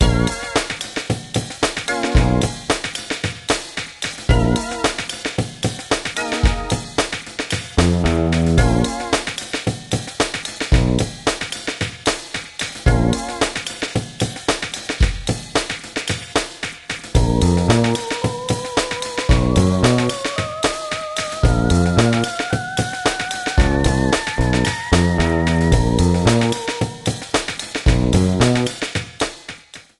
Fair use music sample
trimmed & added fadeout You cannot overwrite this file.